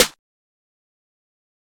SouthSide Snare (35).wav